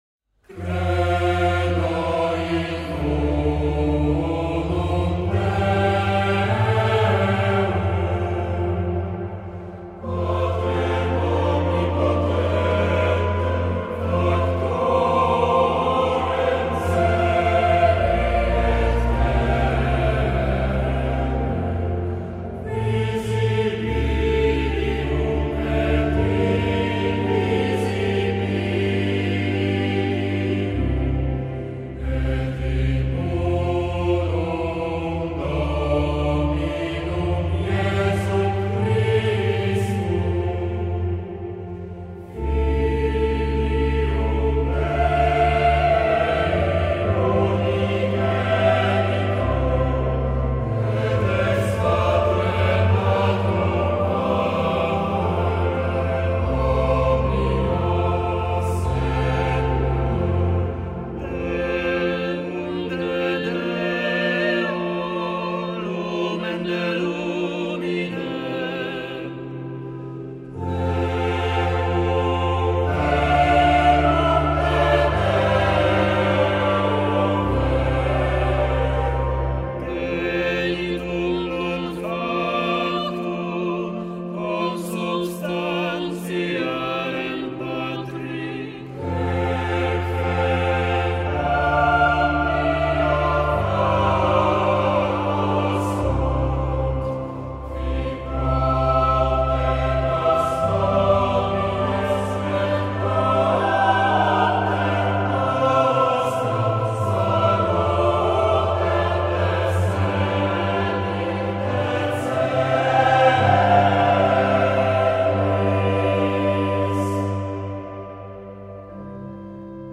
MSZA NR VII na chór mieszany (SATB), solistów (SATB) i organy
Credo (Moderato, Andante, Allegro ma no n assai - SATB i SATB - solo)
organy/organ
Chłopięcy Chór Katedralny / Cathedral Boys' Choir